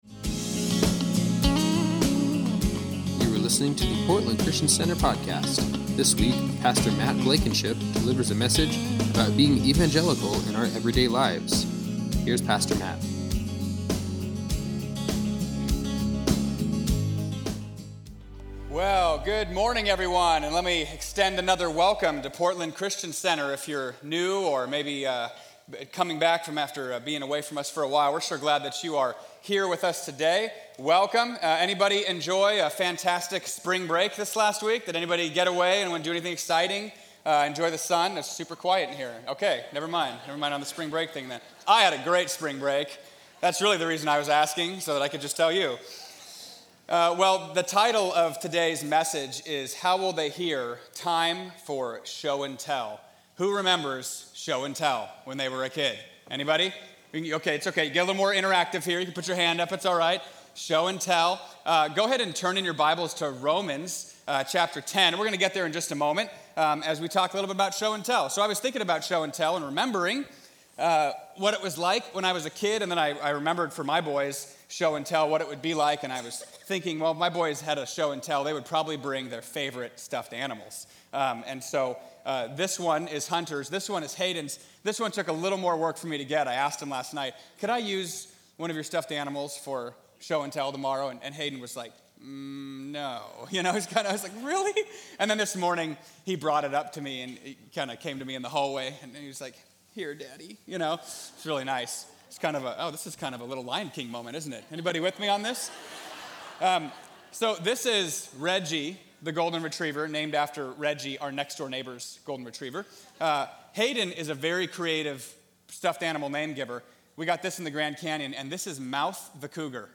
Sunday Messages from Portland Christian Center Time For Show And Tell Mar 31 2019 | 00:42:04 1x 00:00 / 00:42:04 Subscribe Share Spotify RSS Feed Share Link Embed